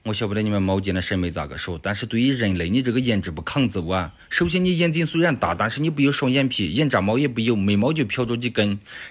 用于戏剧性内容的逼真愤怒老板配音
使用最逼真的 AI 语音技术，为您的项目创建具有命令性、愤怒和激烈感的音频。
文本转语音
高强度
权威语气